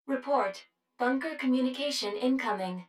153_Bunker_Communication.wav